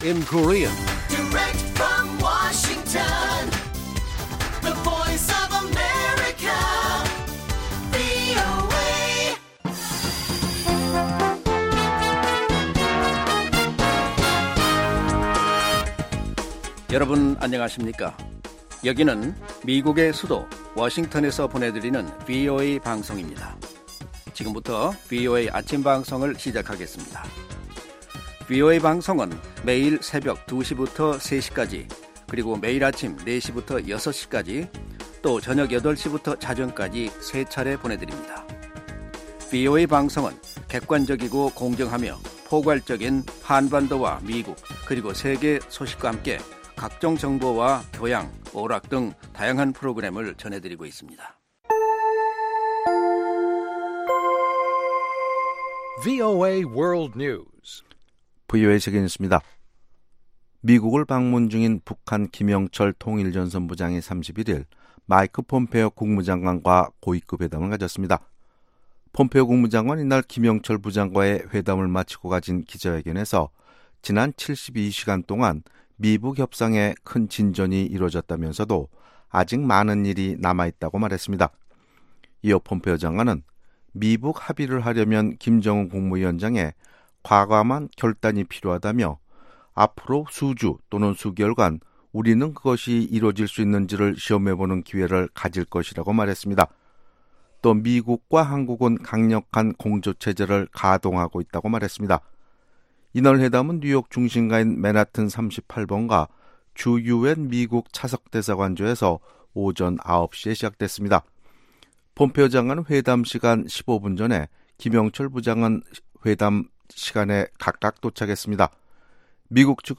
세계 뉴스와 함께 미국의 모든 것을 소개하는 '생방송 여기는 워싱턴입니다', 2018년 6월 1일 아침 방송입니다. ‘지구촌 오늘’에서는 미군 태평양사령부가 인도-태평양사령부로 이름을 바꾼 소식, ‘아메리카 나우’에서는 전 FBI 국장 해임 관련 메모가 공개된 소식을 전해드립니다. '인물 아메리카'에서는 지난주에 이어 미국 현대음악을 대표하는 작곡가 조지 거슈윈의 삶과 음악을 소개합니다.